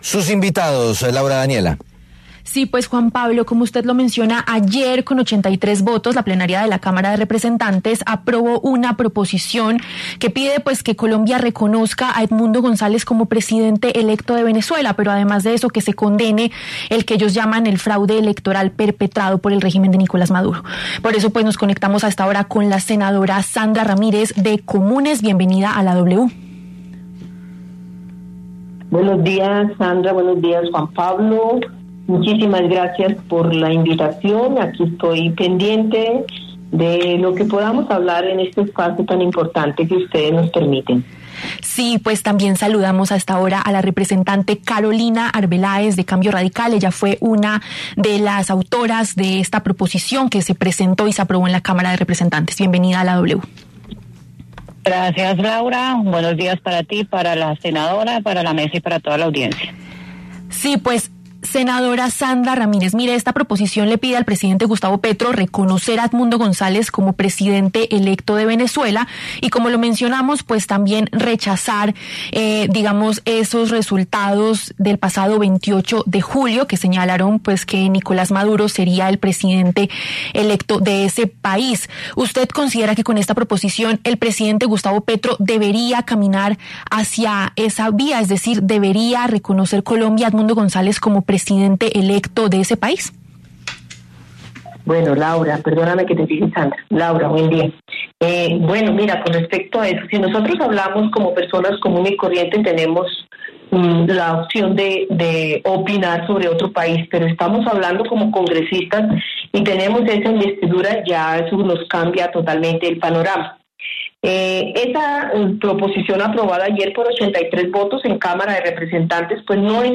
La senadora Sandra Ramírez, del partido Comunes, y la de la representante Carolina Arbeláez, de Cambio Radical pasaron por los micrófonos de La W. Debatieron sobre la proposición que aprobó la Cámara para rechazar la situación política en Venezuela.